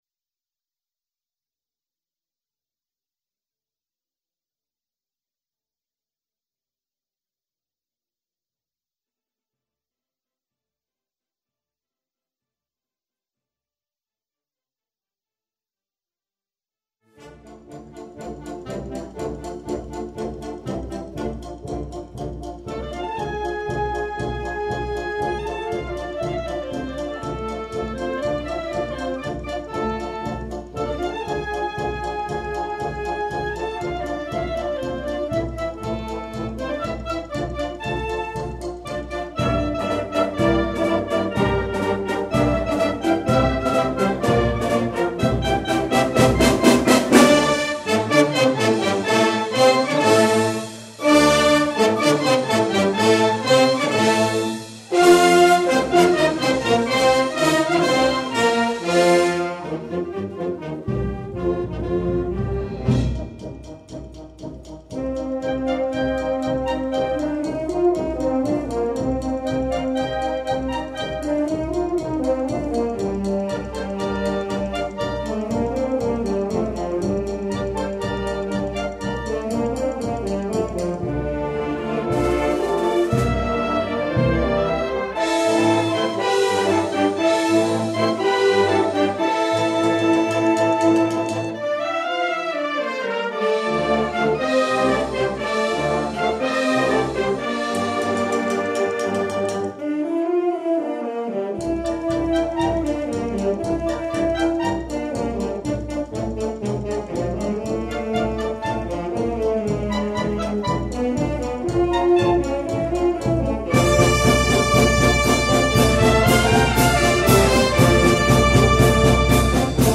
Alcune registrazioni di brani eseguiti dalla Banda di Ancarano
Esecuzioni esclusivamente dal vivo (quindi con tutte le stecche e le stonature che possono capitare in concerto... abbiate pietà!)
Concerto di Santa Cecilia 2004 - 25° Anniversario della fondazione della Banda
Cinesina - Marcia Caratteristica - A. Lamanna